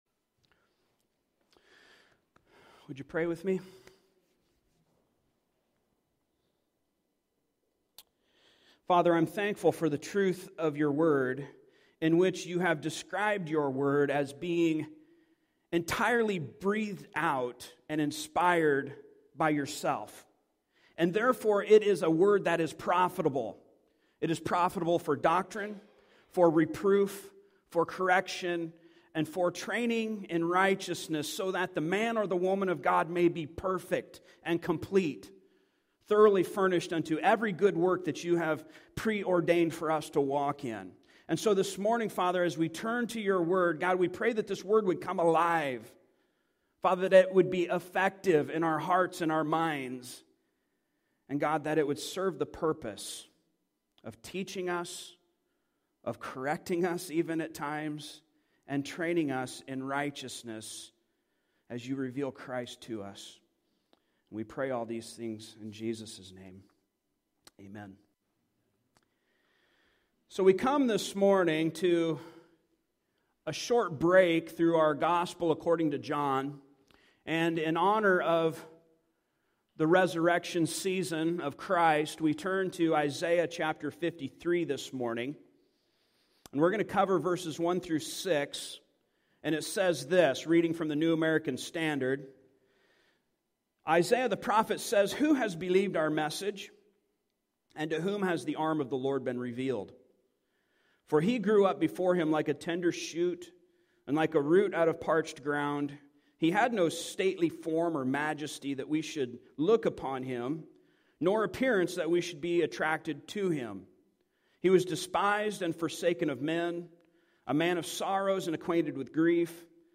Service Type: Sunday Morning Topics: Cross , Jesus Christ , Salvation